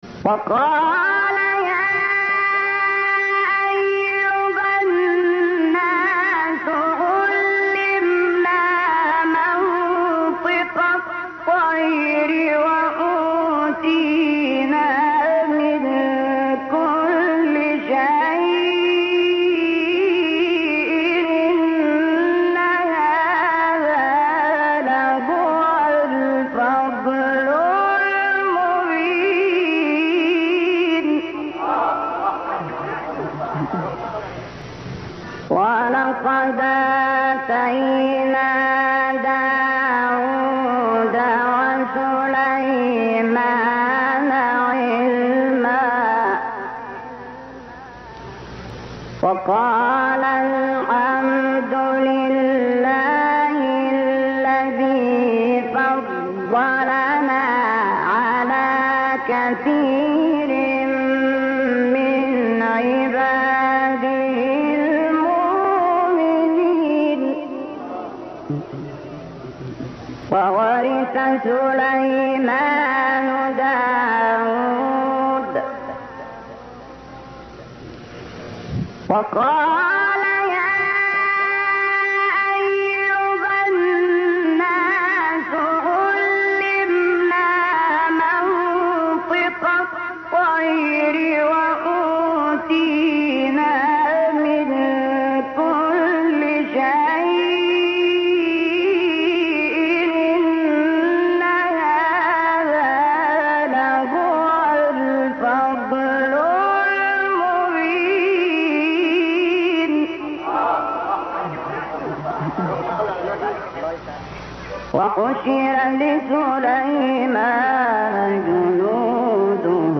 أسم القارئ الشحات محمد انور